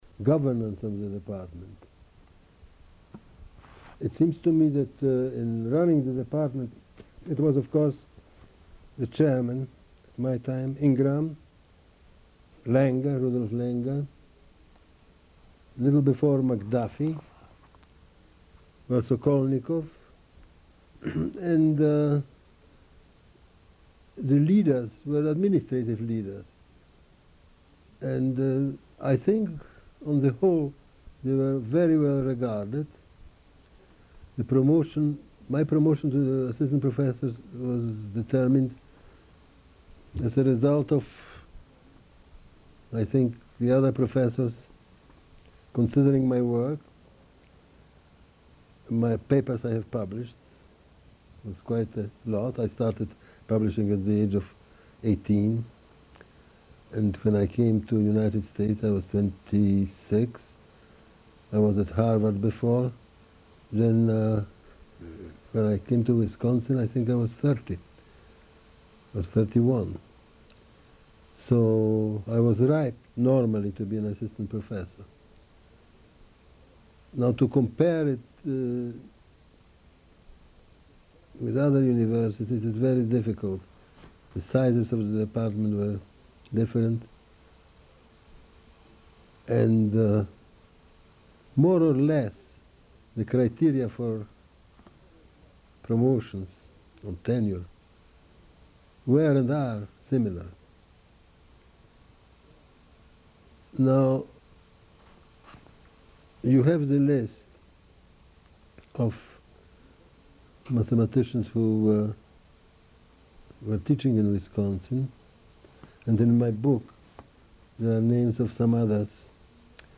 Oral History Interview: Stanislaw M. Ulam (0363)